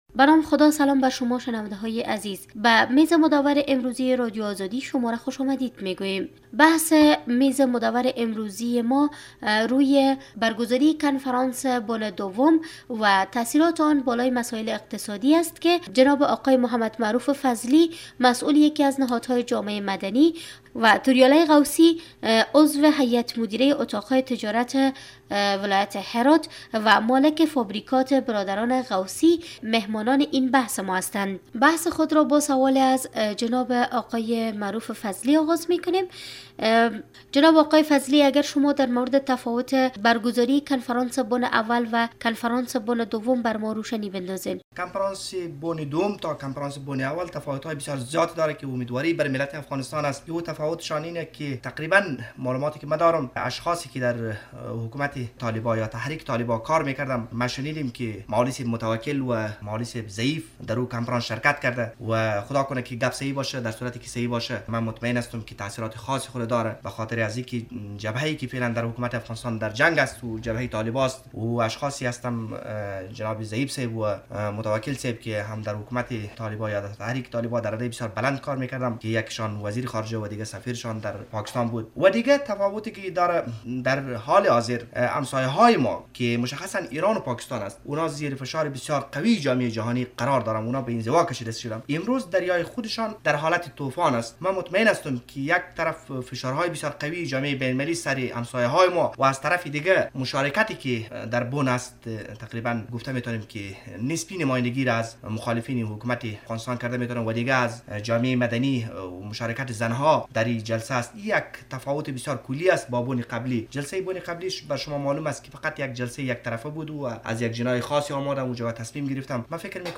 بحث میز گرد ولایت هرات